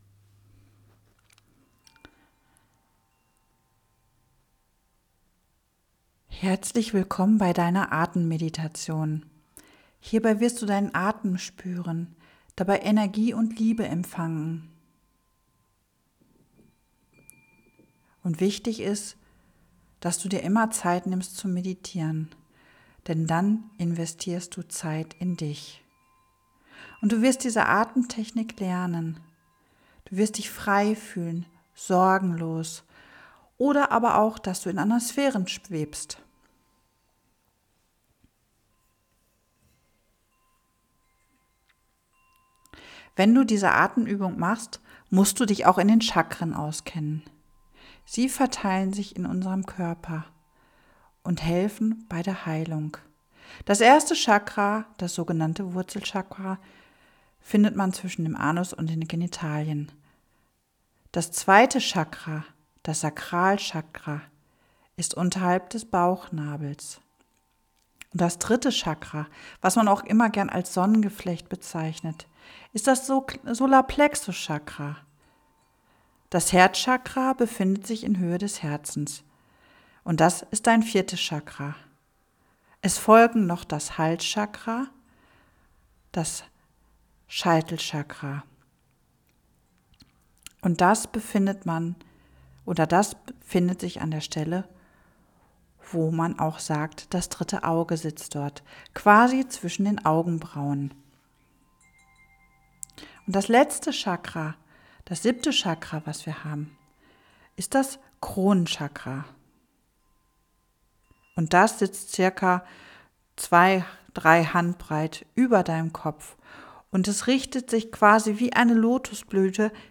Chakra-Atemuebung.m4a